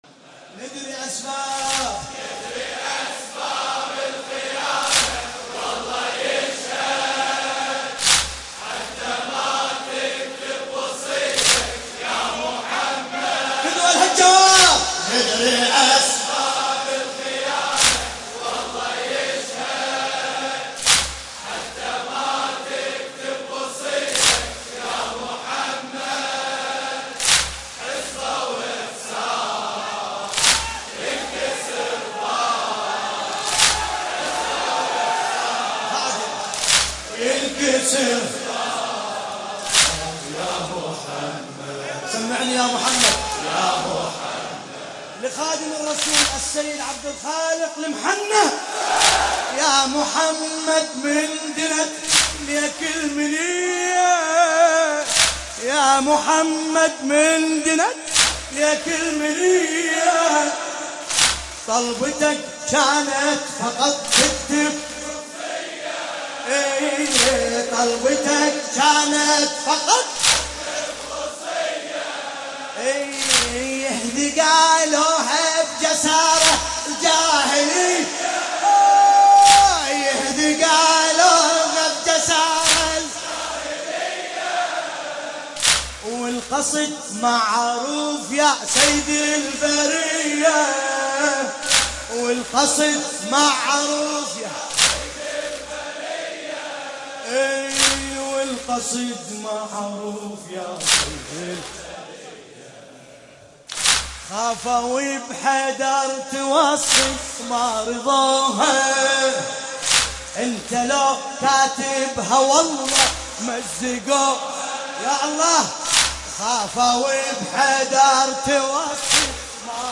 ملف صوتی خيانة الوصية بصوت باسم الكربلائي
لطميات
الرادود : الحاج ملا باسم الكربلائي